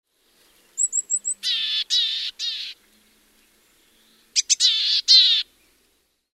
Poecile montanus
Kutsuääni on tunnistettava tsi-tsi-tsää-tsää-tsää. Laulu surumielinen sarja tjyy-tjyy-tjyy-tjyy…